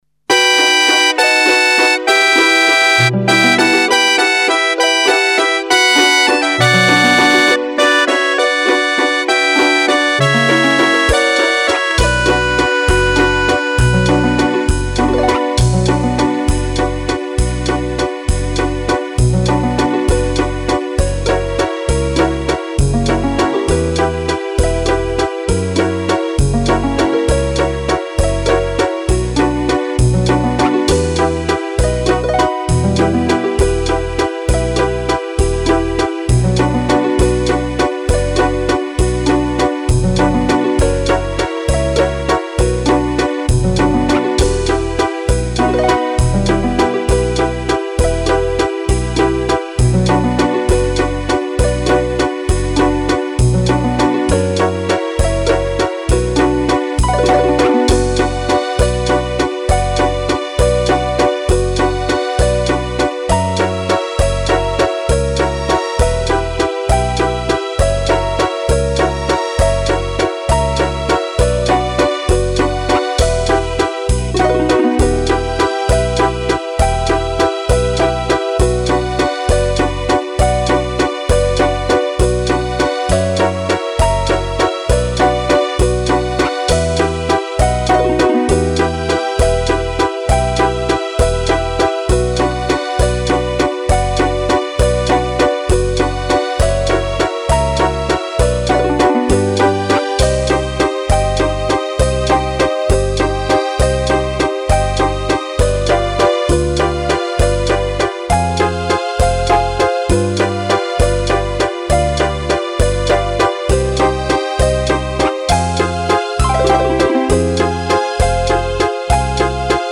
Если сами надумаете спеть - это же в минусовом варианте